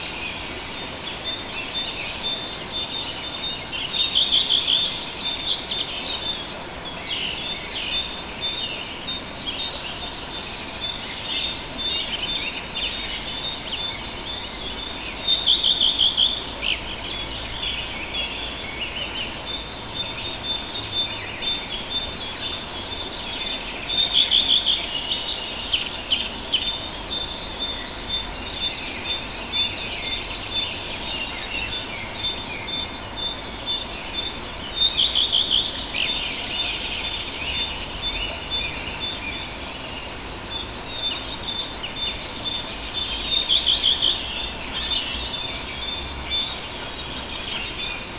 • early mountain birds habitat 6.wav
Great place to record the natural habitat of birds and animals in the beautiful Southern Carpathian Mountains. Recorded with Tascam DR 40